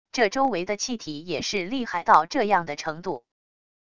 这周围的气体也是厉害到这样的程度wav音频生成系统WAV Audio Player